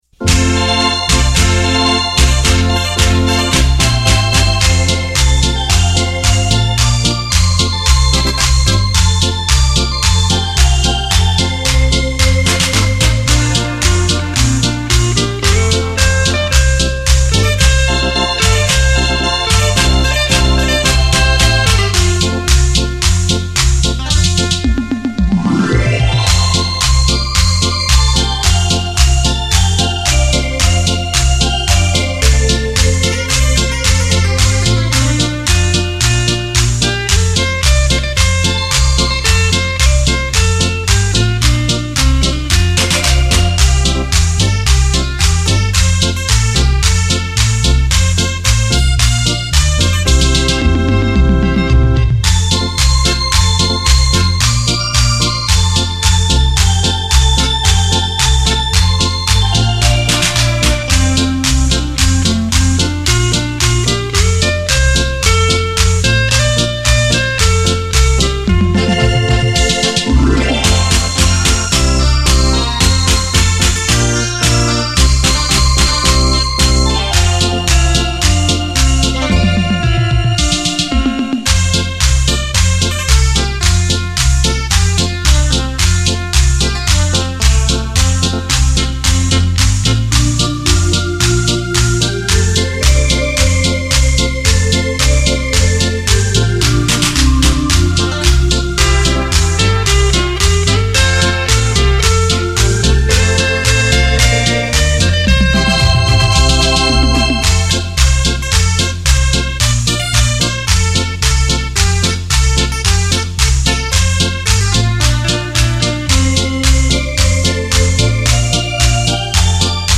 专辑格式：DTS-CD-5.1声道
繞場立體音效 發燒音樂重炫
最新編曲演奏，台語老歌新奏，節奏強勁，旋律優美，曲曲動聽
電聲演繹發燒珍品·值得您精心收藏·細細聆賞...